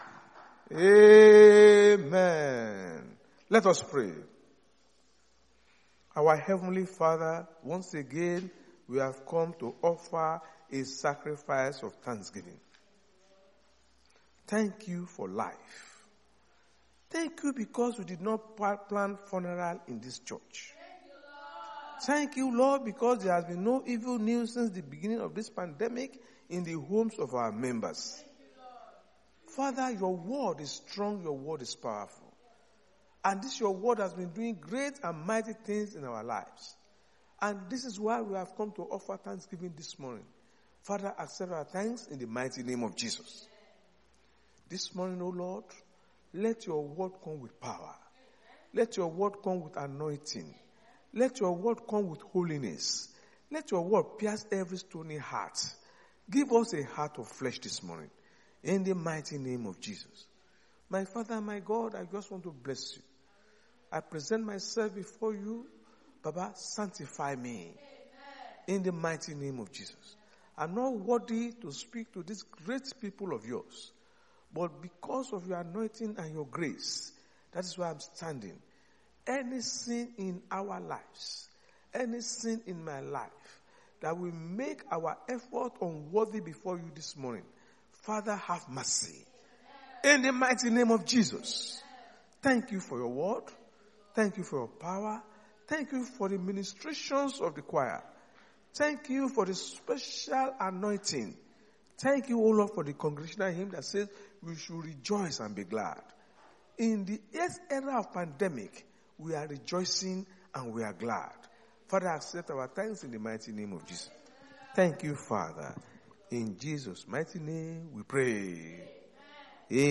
RCCG-House Of Glory sunday sermons.
Service Type: Sunday Church Service